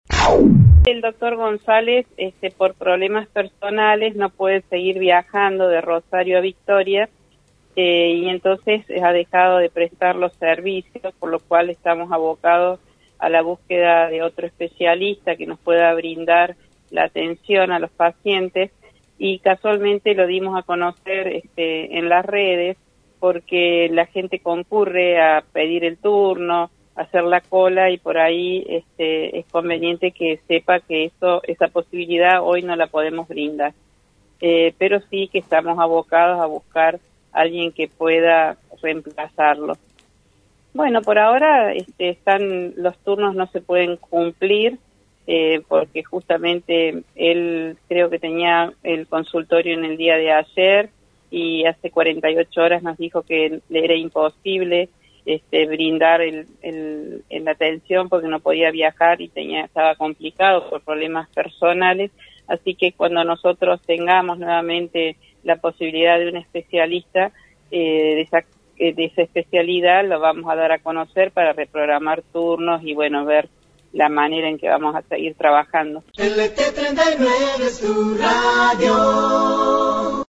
En diálogo con FM 90.3